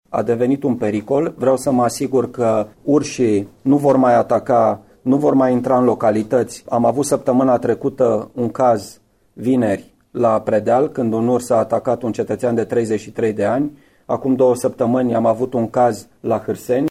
Prefectul judeţului, Marian Rasaliu susține că va cere Ministerului Mediului să aprobe recoltarea mai multor exemplare de urs: